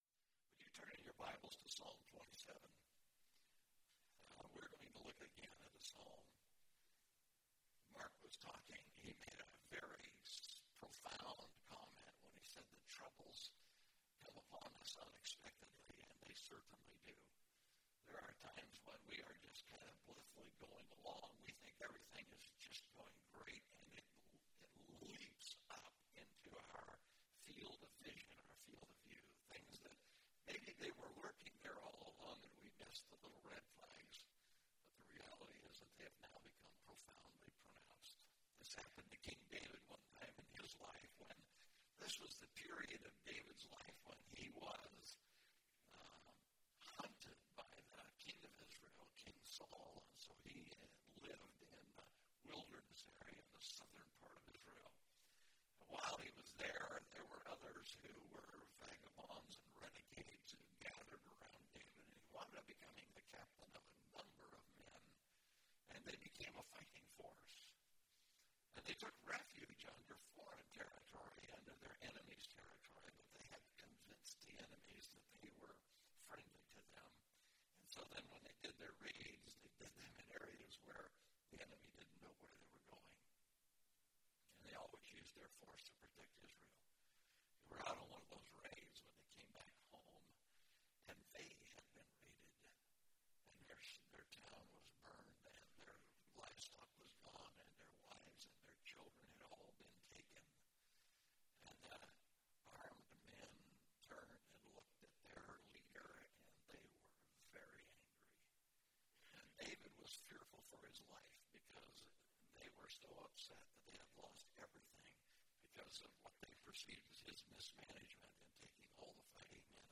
Heaven Is My Home (Psalm 27) – Mountain View Baptist Church
Topical Message